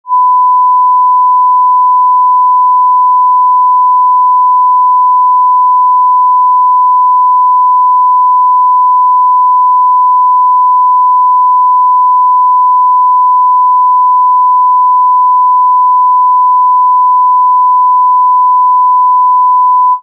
1 KHz sinus wave at -3 dB 00' 20" sinus006
sinus006.mp3